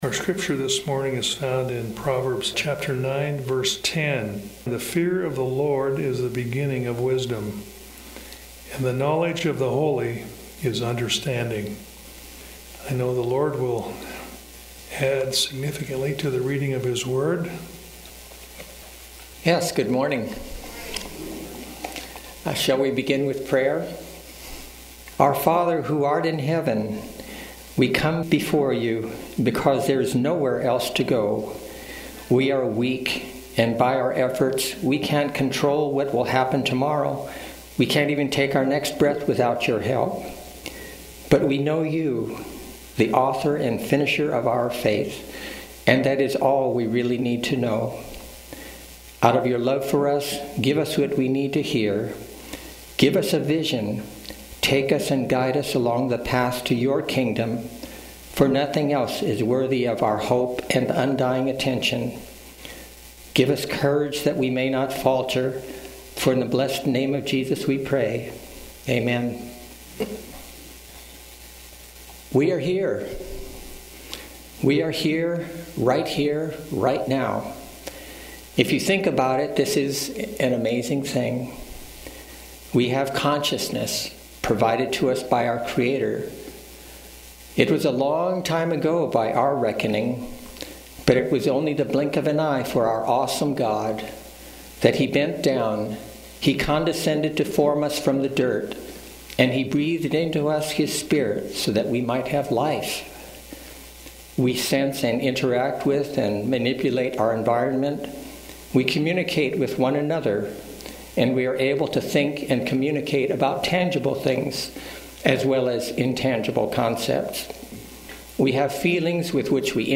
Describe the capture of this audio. Seventh-day Adventist Church, Sutherlin Oregon